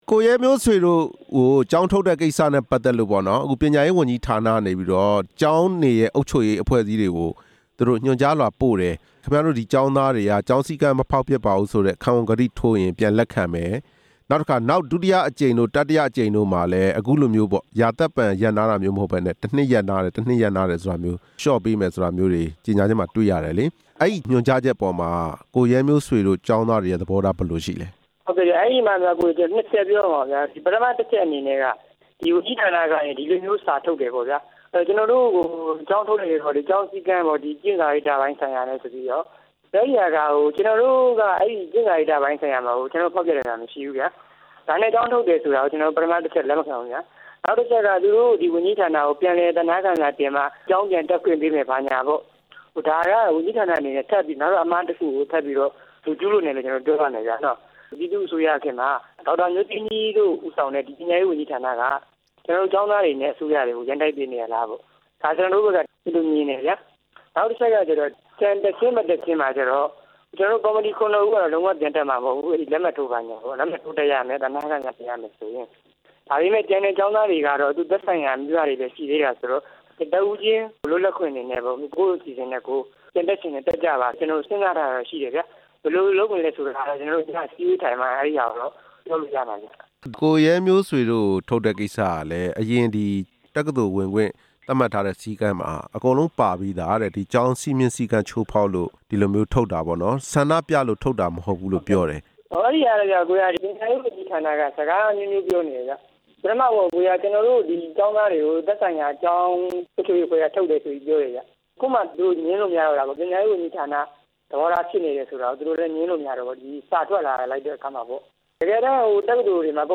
ကျောင်းထုတ်ခံရတဲ့ ဆန္ဒပြကျောင်းသားနဲ့ မေးမြန်းချက်